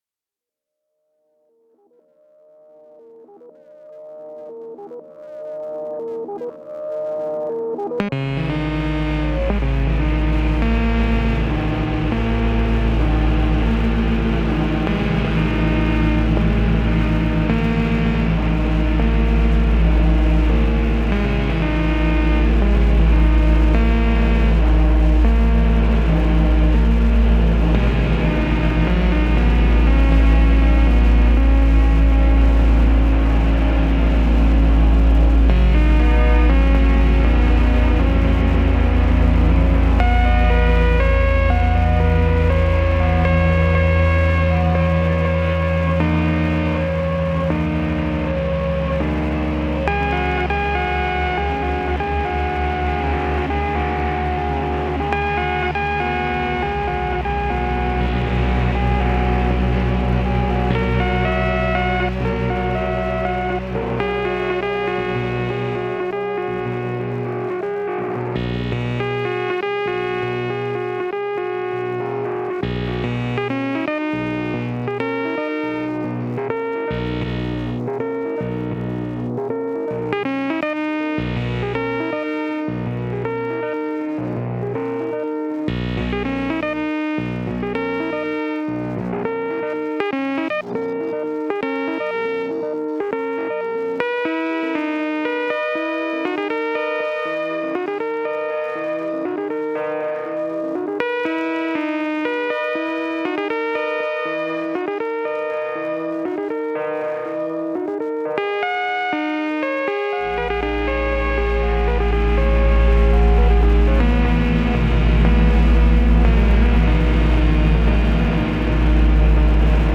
To pass time, he would imitate the sounds of engines, animals, anything that made noise.
Michael Leslie Winslow (born September 6, 1958) is an American actor, comedian and beatboxer billed as The Man of 10,000 Sound Effects[1] for his ability to make realistic sounds using only his voice.